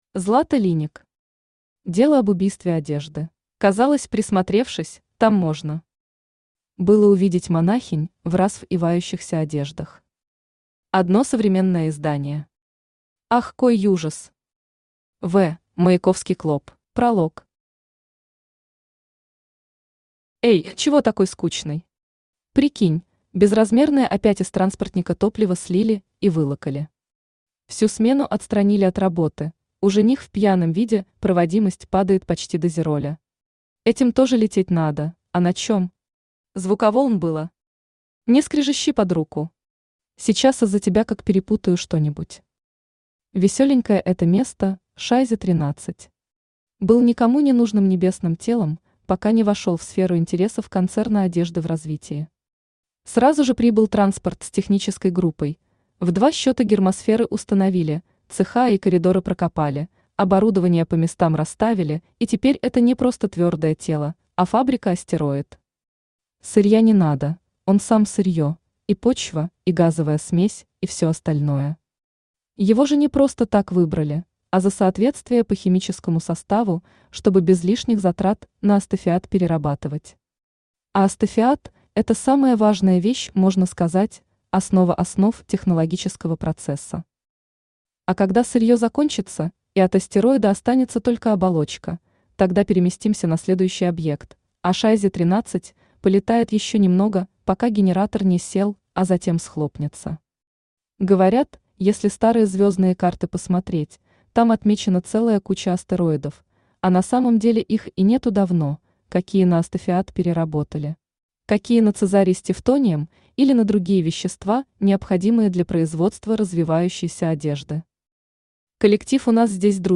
Аудиокнига Дело об убийстве одежды | Библиотека аудиокниг
Aудиокнига Дело об убийстве одежды Автор Злата В. Линник Читает аудиокнигу Авточтец ЛитРес.